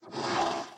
Minecraft Version Minecraft Version latest Latest Release | Latest Snapshot latest / assets / minecraft / sounds / mob / horse / skeleton / idle2.ogg Compare With Compare With Latest Release | Latest Snapshot